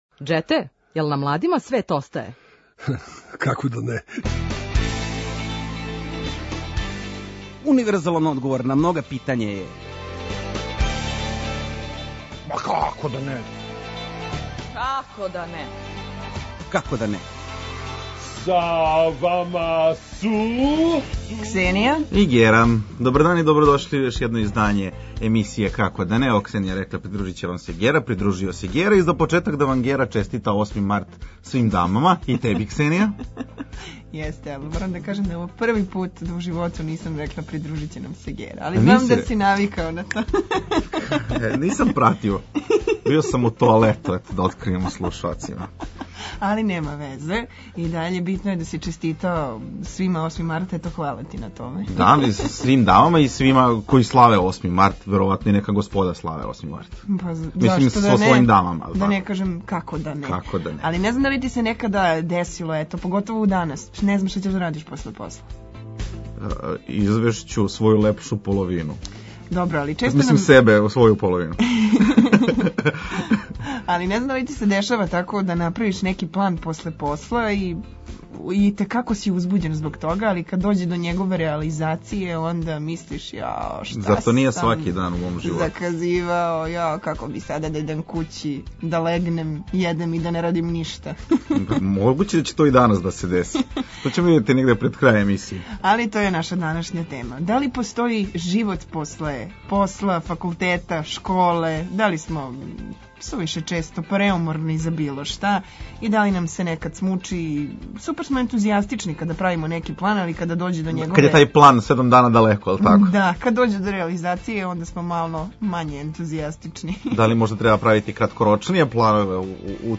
Окосница емисије је „Дебата” у којој ћемо разменити мишљења о различитим темама и дилемама.